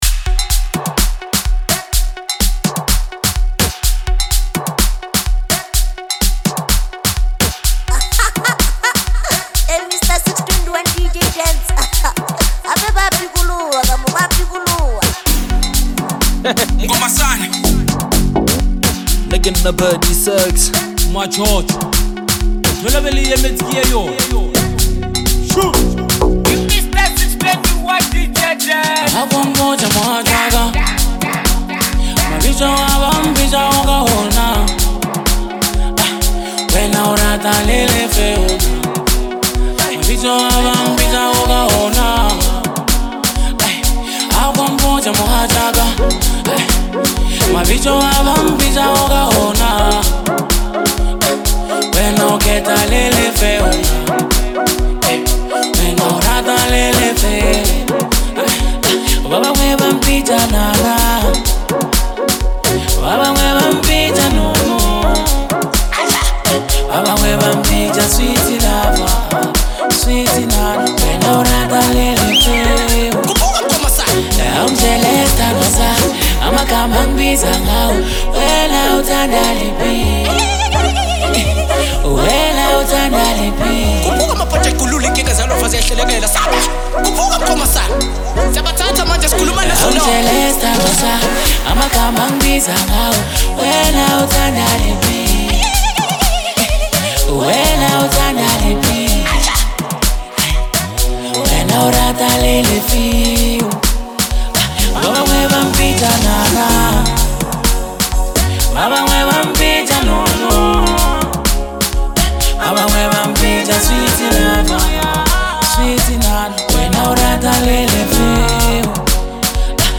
culturally rich and emotionally grounded song